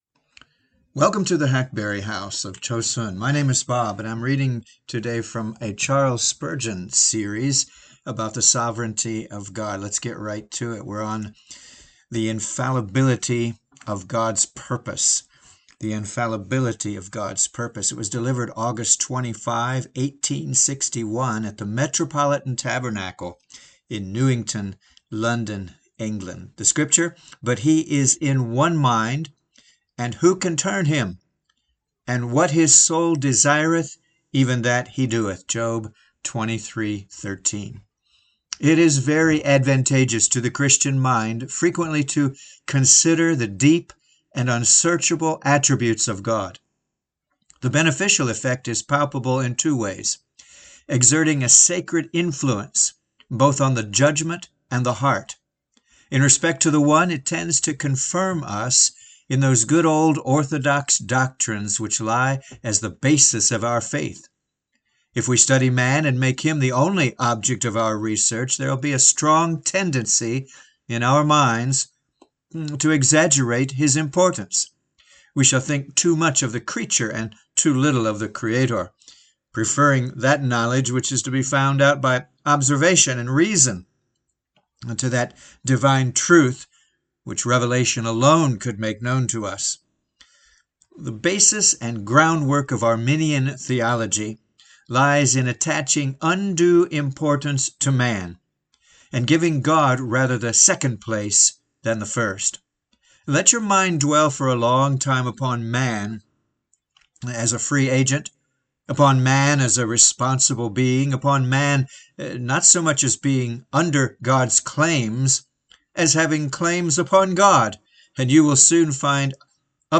Infallibility of God's Purpose | SermonAudio Broadcaster is Live View the Live Stream Share this sermon Disabled by adblocker Copy URL Copied!